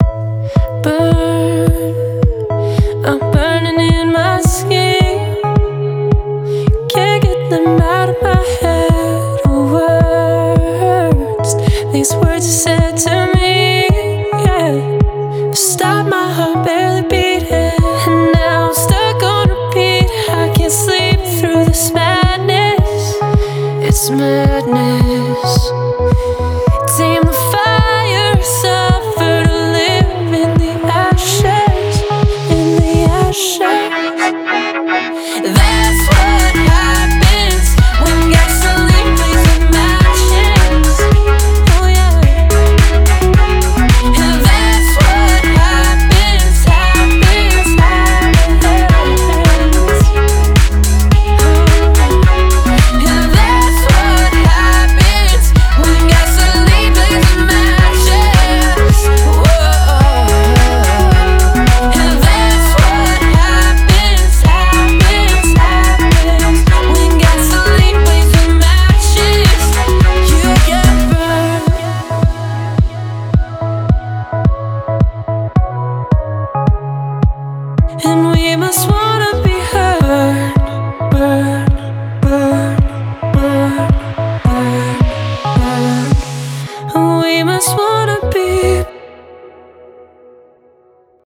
BPM108